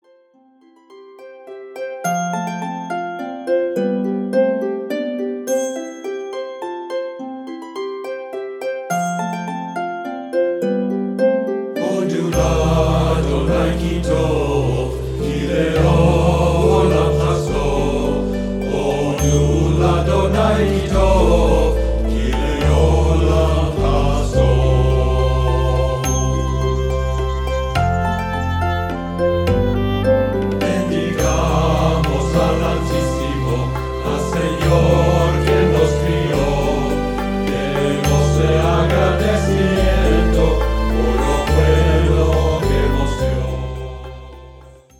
Indian musical instruments
Trad. Ladino